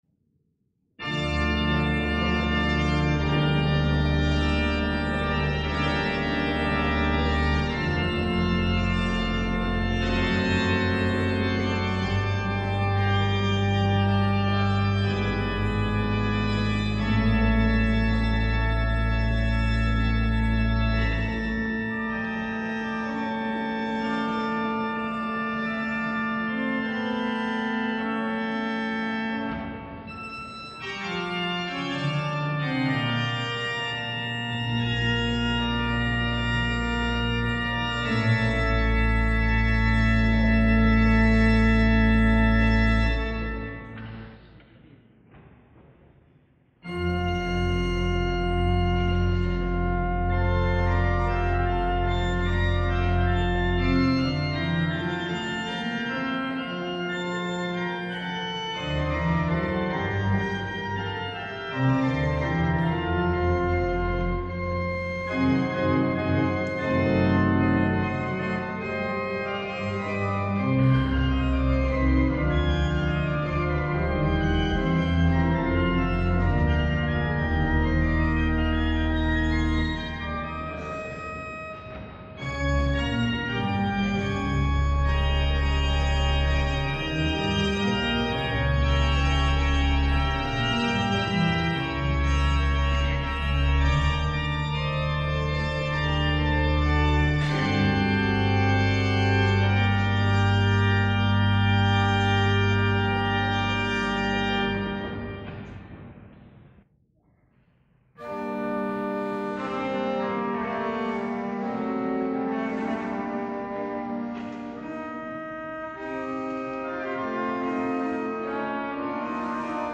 San Gregorio in Cavalpone (VR), Maggio 1996
Concerto di inaugurazione
Live
con Accademia Strumentale di Toscana